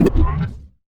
Robotic Back Button 1.wav